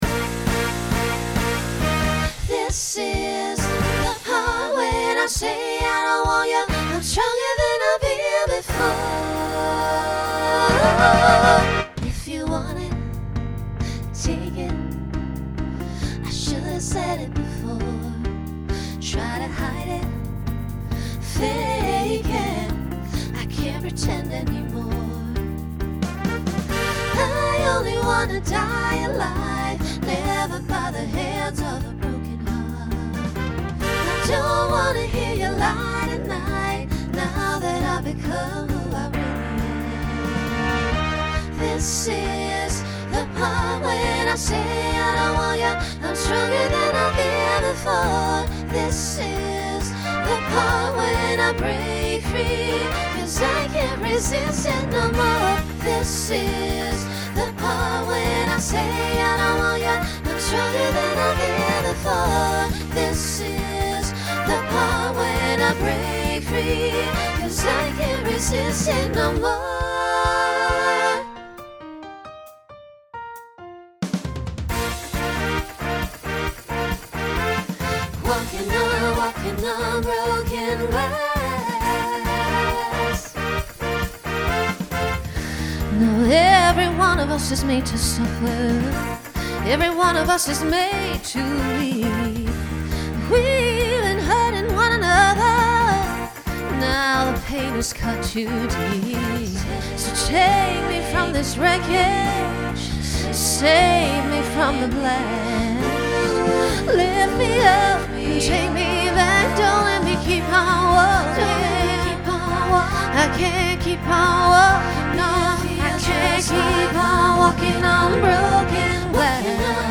Genre Pop/Dance
Voicing SSA